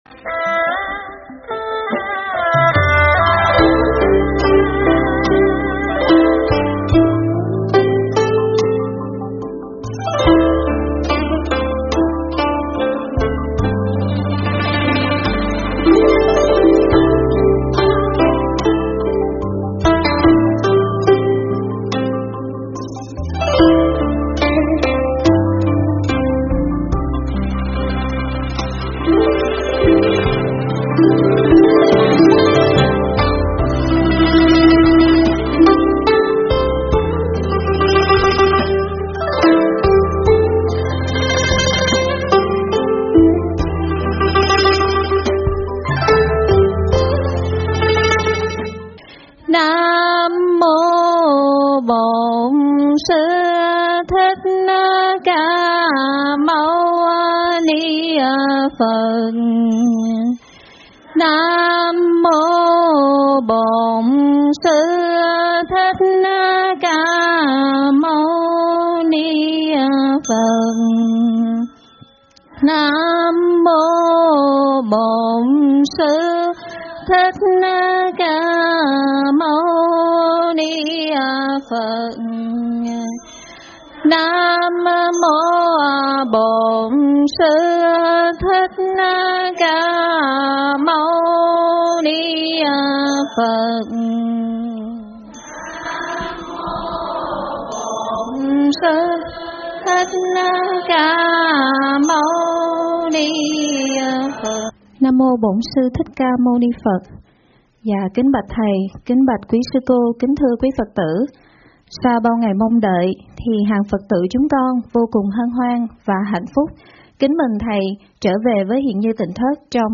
Thuyết pháp Hướng Tâm và Trú Tâm
tại tịnh Thất Huyền Như